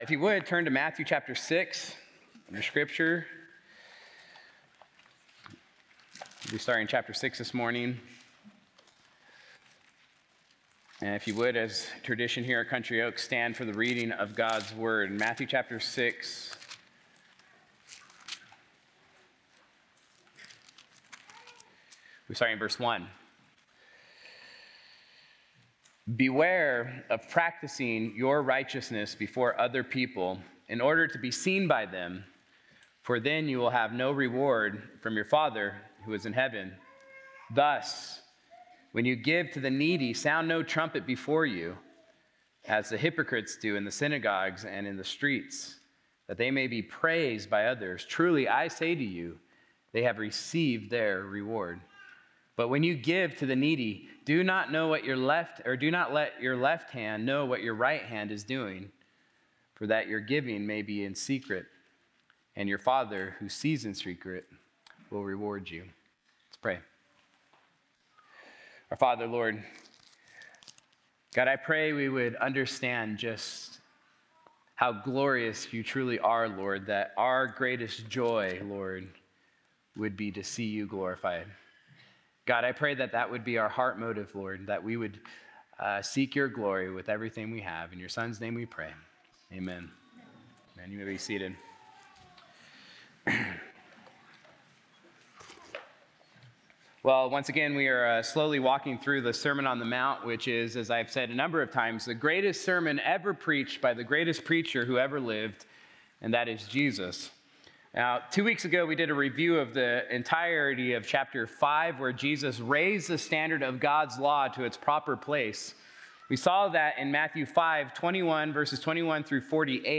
Sunday-Sermon-November-30-2025.mp3